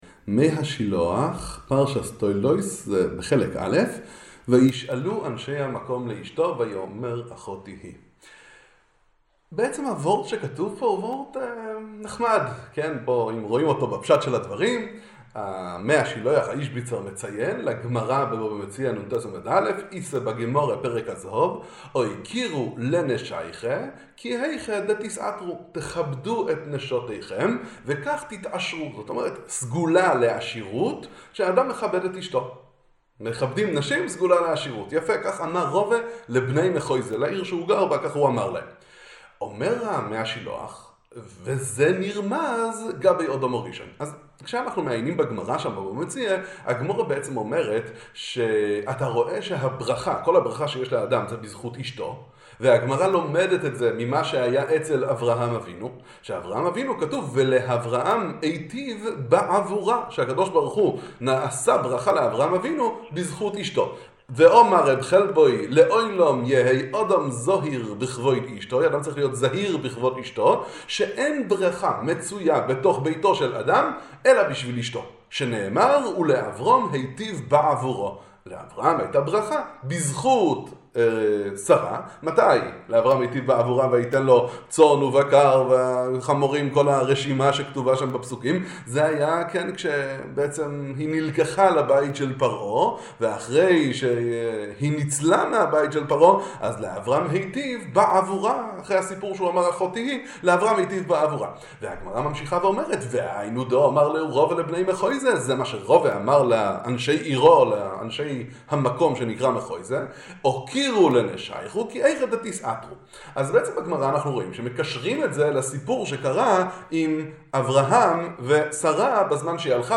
דבר תורה לפרשת השבוע